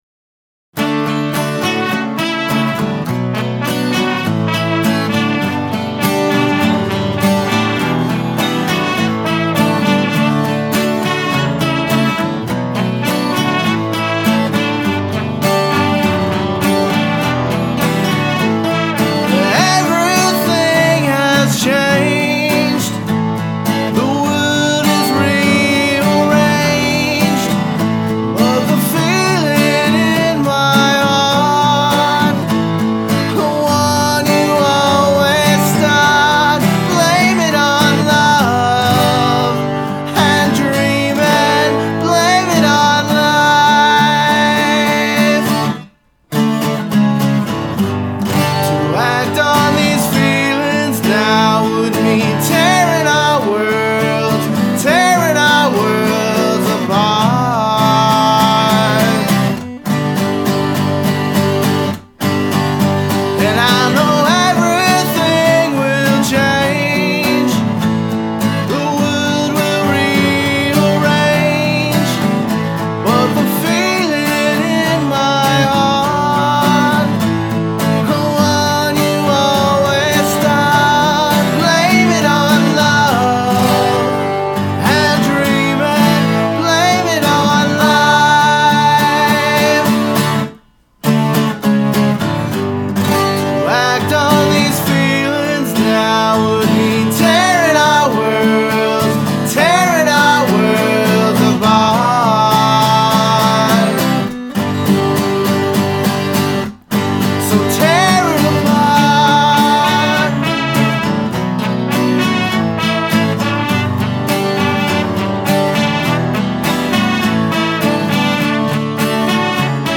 Vocals, Guitars, Bass, Harmonica, Trumpet